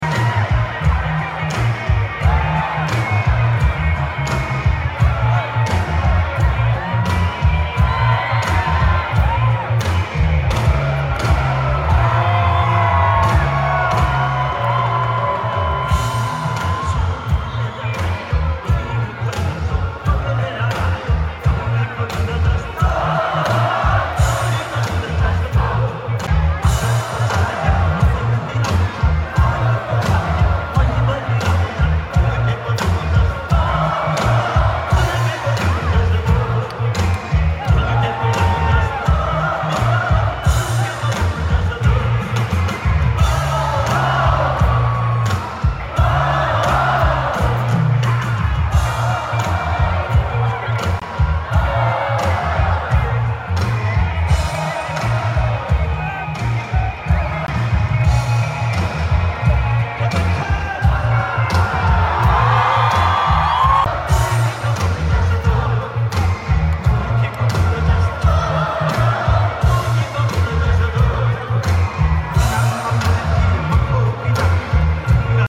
What a energy ♥ always wanted to attend his live show